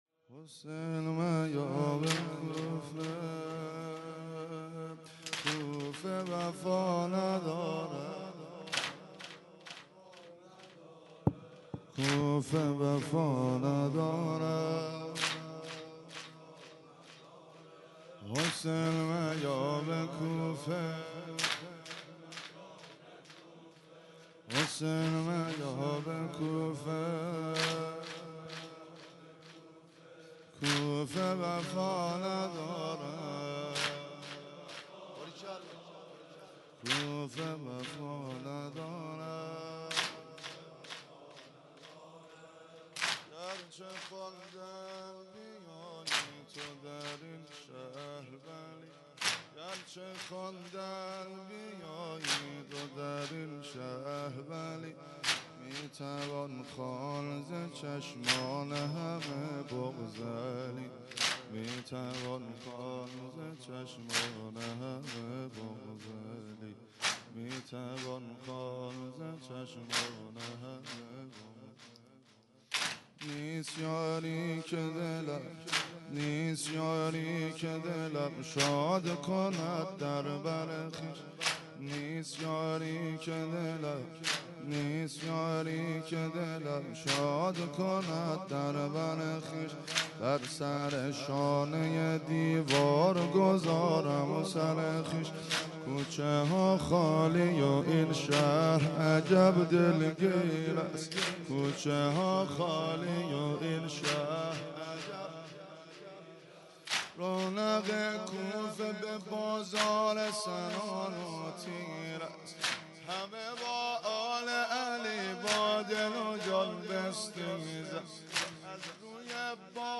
6- حسین میا به کوفه - واحد